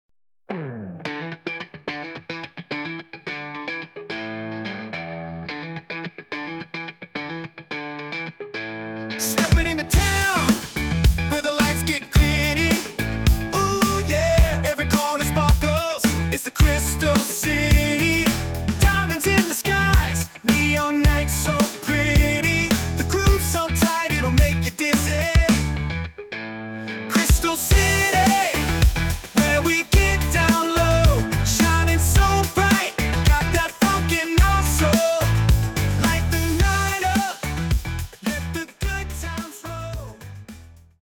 Short version of the song, full version after purchase.
An incredible Rock song, creative and inspiring.